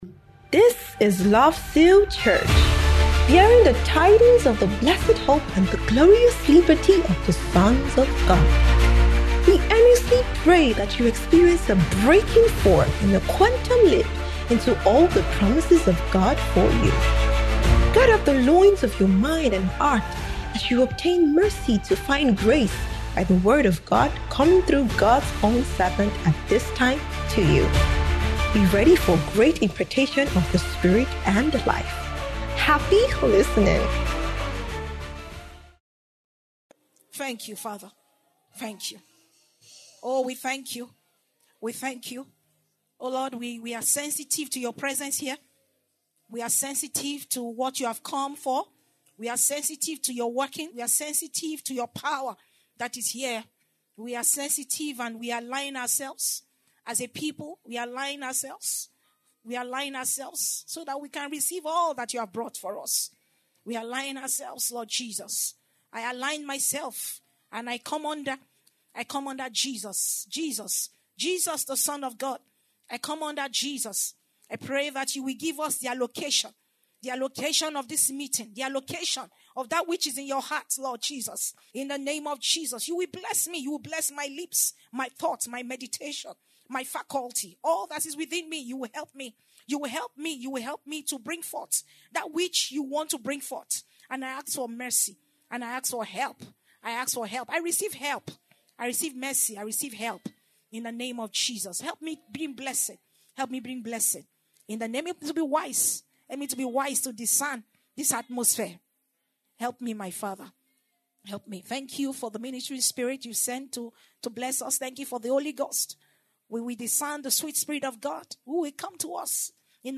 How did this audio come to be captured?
Transfiguration Bootcamp 2025 (D2PM)